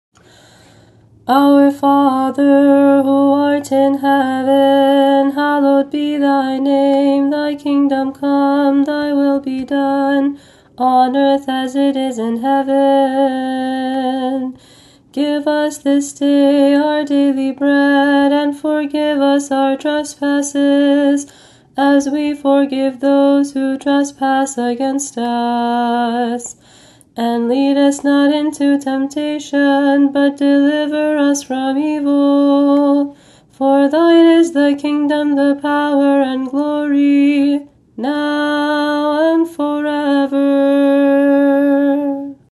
They’re set to the traditional melodies, but in English, with close attention given to the stress of the syllables so it sounds as not-awkward as possible.